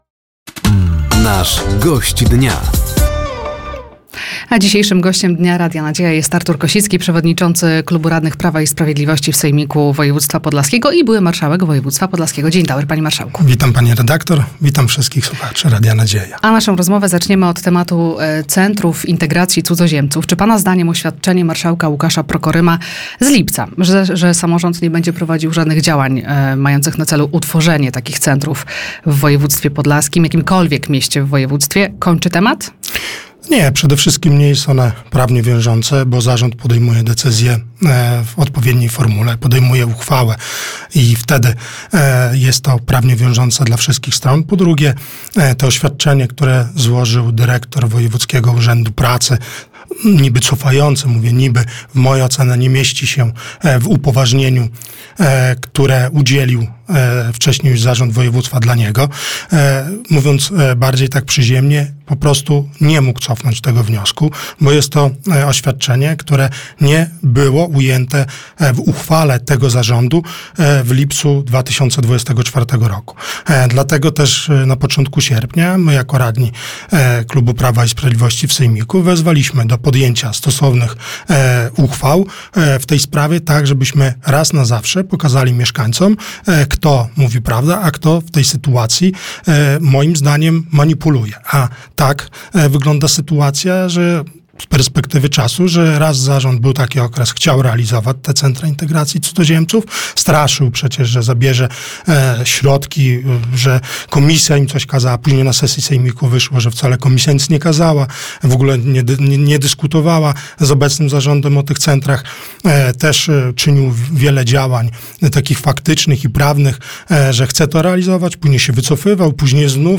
Gościem Dnia Radia Nadzieja był Artur Kosicki, radny województwa podlaskiego. Tematem rozmowy były Centra Integracji Cudzoziemców oraz spółka PKS Nova.